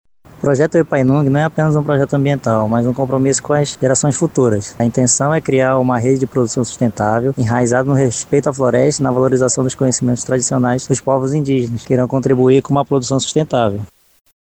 O projeto aposta no manejo florestal não-madeireiro e na ampliação dos Sistemas Agroflorestais (SAFs) como ferramentas essenciais para garantir segurança alimentar, estimular a economia local e manter os estoques de carbono na região. A ideia é apoiar e fortalecer as atividades de coletores, artesãos e produtores locais, como explica o engenheiro florestal